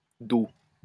wymowa:
IPA/do/ ?/i